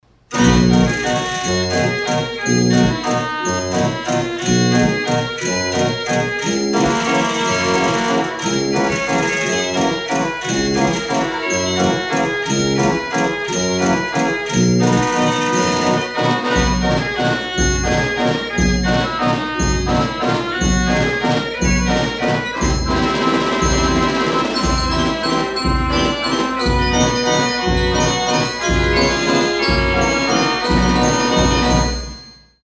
Carousel Fair Organ
her music is very exhilarating